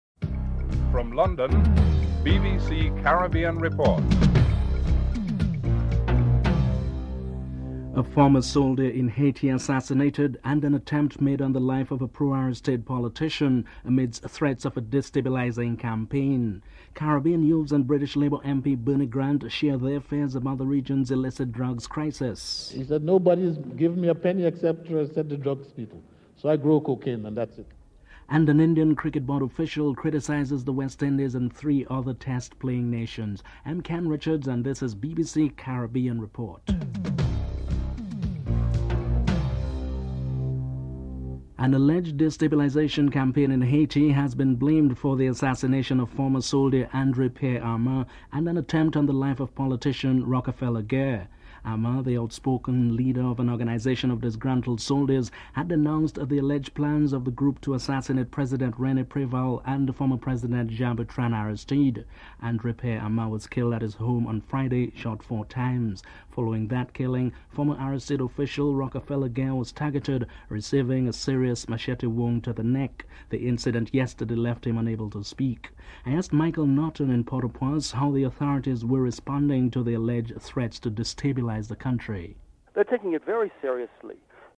1. Headlines (00:00-00:40)
3. Rules of engagement which would press for respect for women in politics is the way forward St. Lucian Prime Minister Vaughn Lewis has announced while addressing a seminar in Castries on women in decision making. Attorney General and Minister for Women's Affairs Lorraine Williams and Prime Minister Vaughn Lewis are interviewed (03:39-05:41)